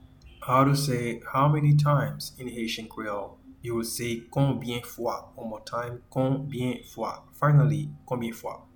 Pronunciation:
How-many-times-in-Haitian-Creole-Konbyen-fwa.mp3